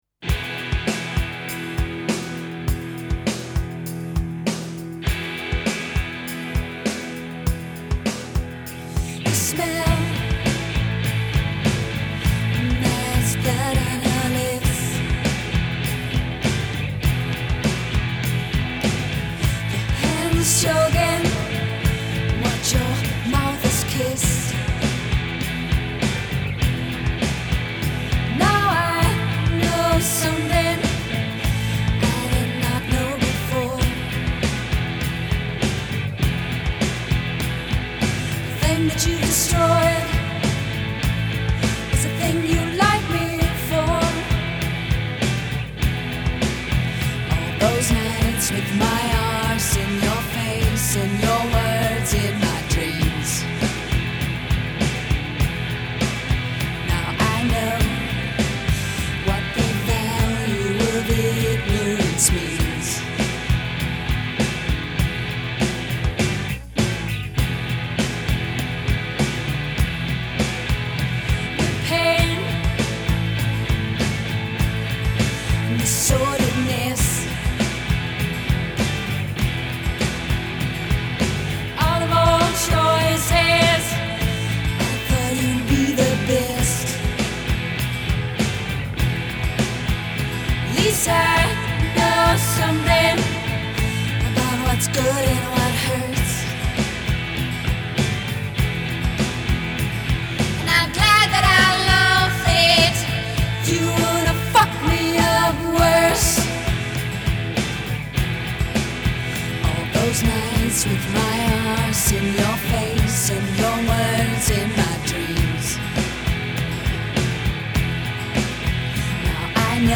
rocking